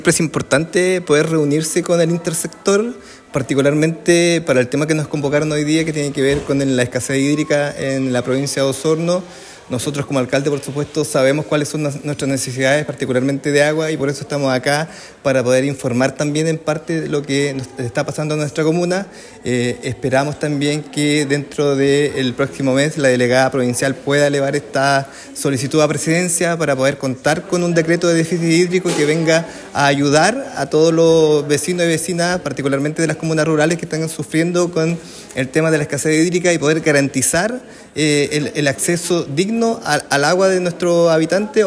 En el marco de la jornada convocada por la Delegación Presidencial Provincial y el Servicio Nacional de Prevención y Respuesta ante Desastres, SENAPRED, el alcalde de San Pablo, Marco Carrillo, subrayó la necesidad de avanzar en la declaración de la provincia de Osorno como zona de escasez hídrica.